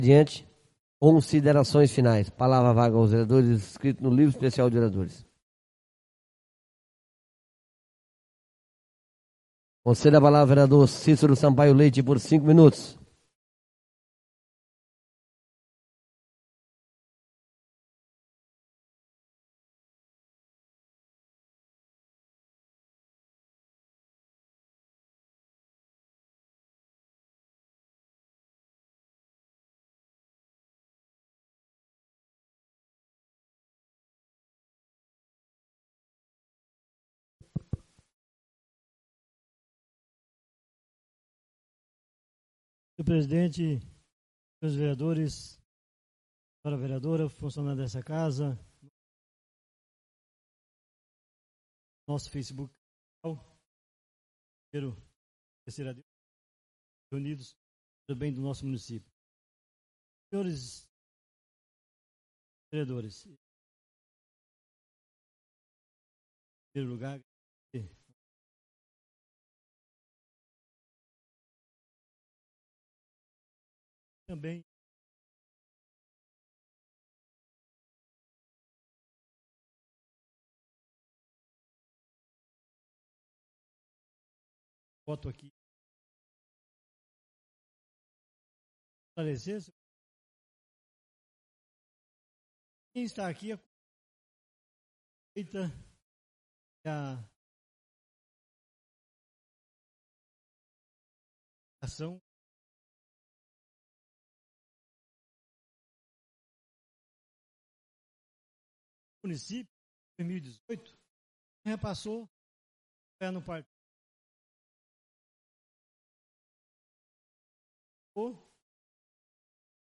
Oradores do Expediente (29ª Ordinária da 4ª Sessão Legislativa da 6ª Legislatura)